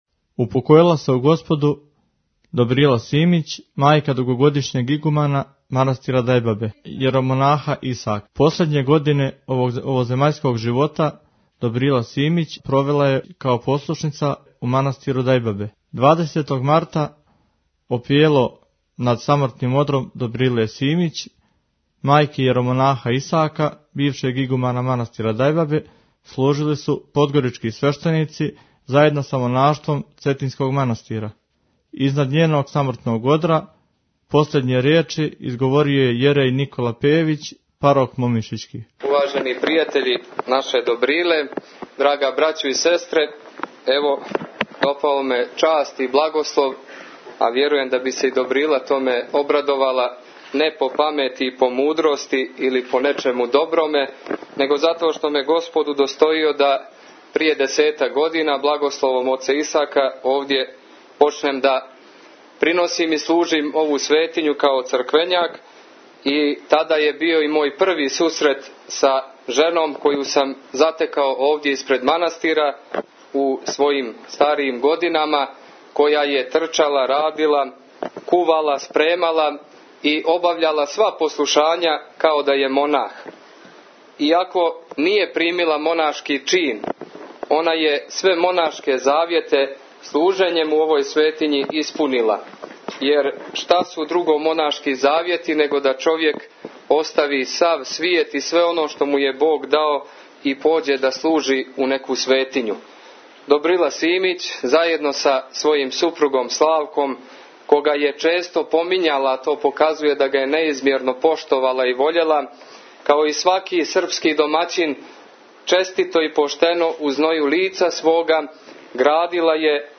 Опело су служили свештеници Подгоричког архијерејског намјесништва и цетињски свештеномонаси.